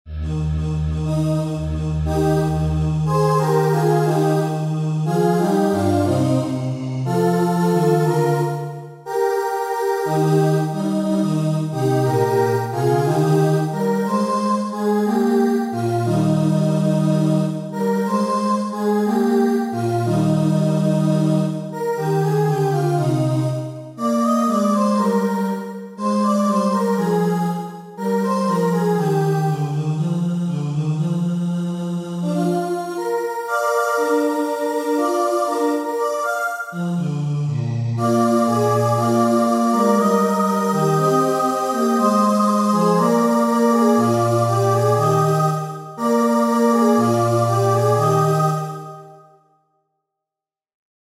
A TRIO
trio.mp3